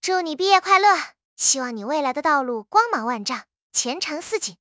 Text-to-Speech
Spark TTS finetuned in genshin charactors voices.